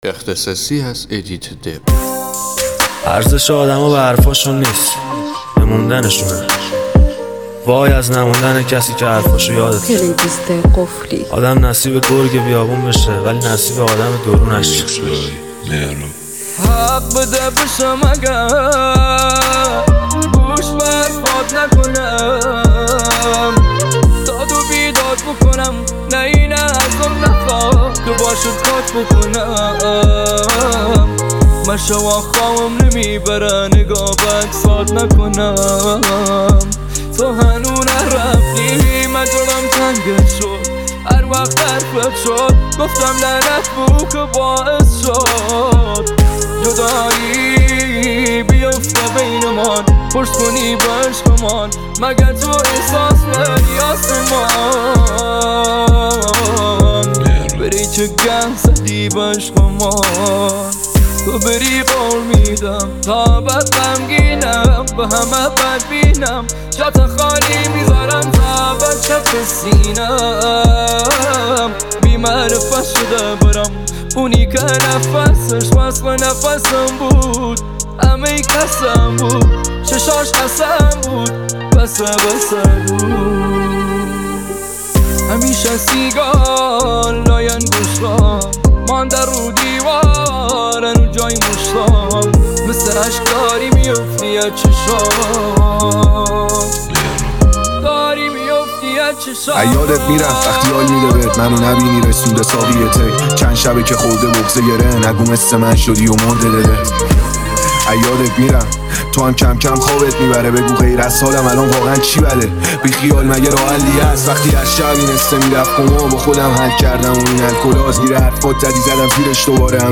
ریمیکس رپی